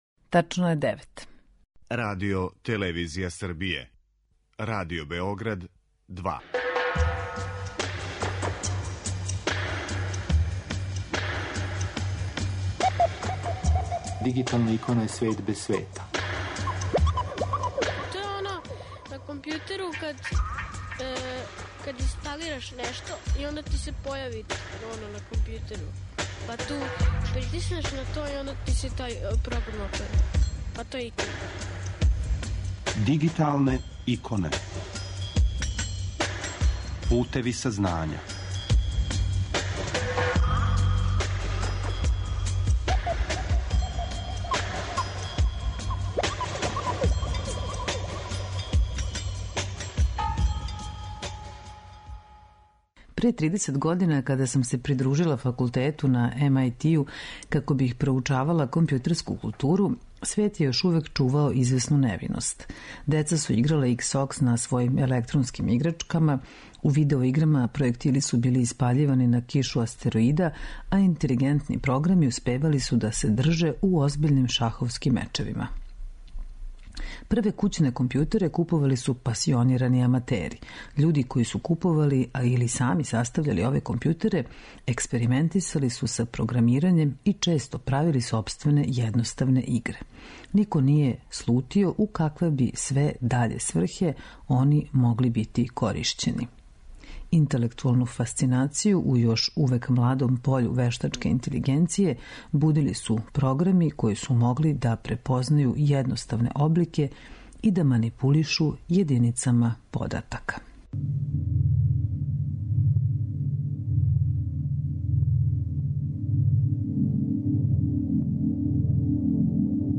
Емисија Дигиталне иконе покренута je 2002. године, а емитује се сваког уторка на таласима Радио Београда 2 од 9 до 10 сати.